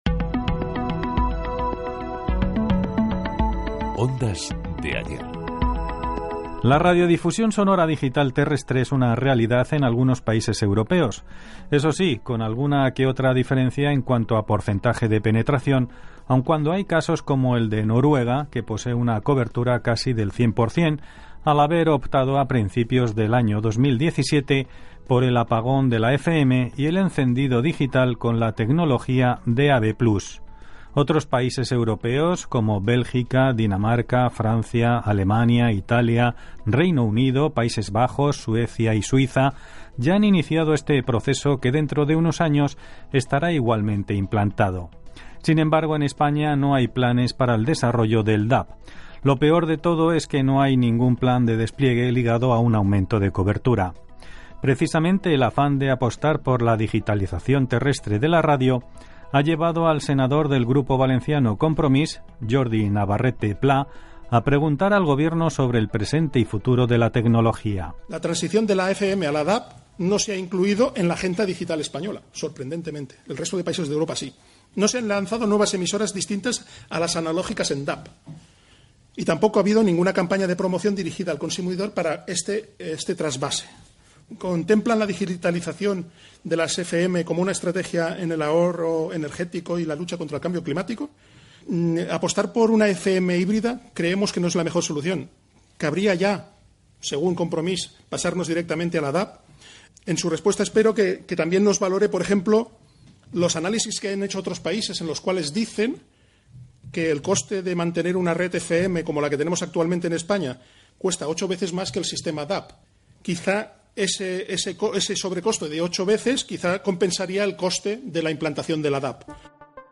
Careta del programa, espai dedicat a la Radiodifusió d'Àudio Digital (DAB). Situació a Europa i a l'Estat espanyol.
Divulgació